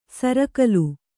♪ sarakalu